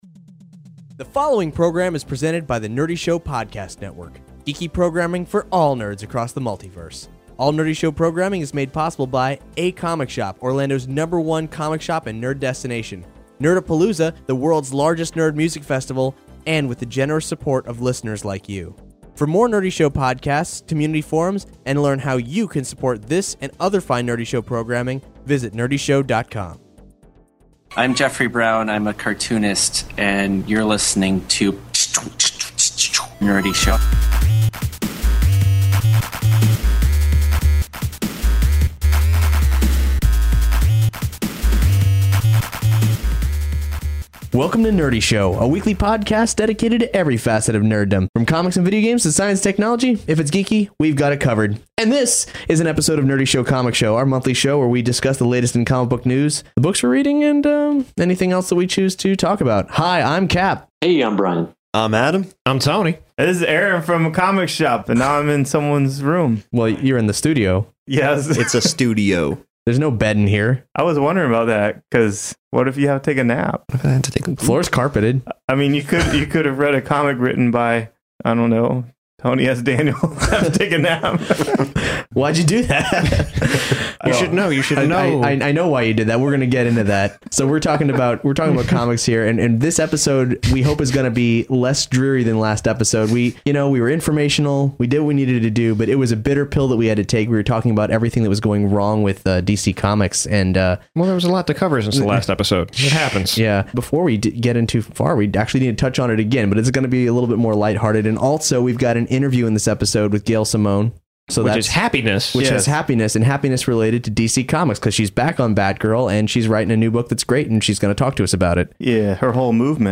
We interview Gail Simone, of Secret Six and Batgirl fame, about her forthcoming projects including a bold new book from DC, The Movement, her fan-funded Leaving Megalopolis, and clashing swords with Red Sonja.